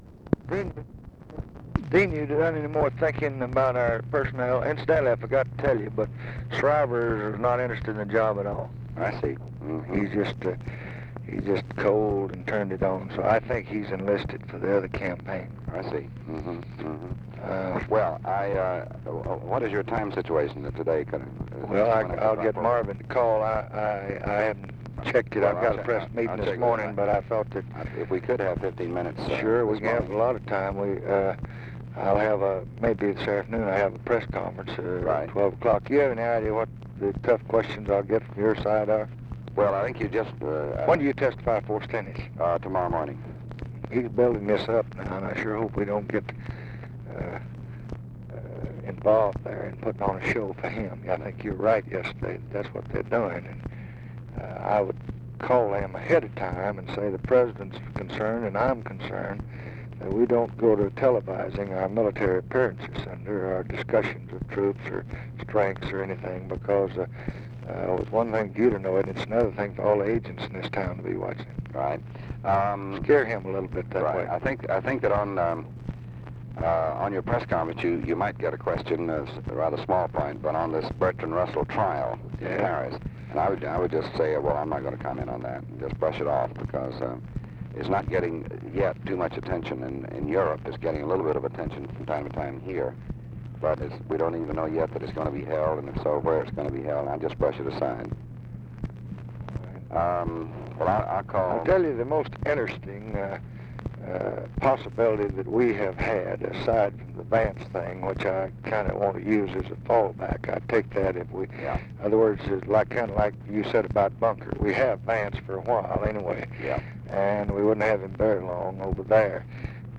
Conversation with DEAN RUSK, August 24, 1966
Secret White House Tapes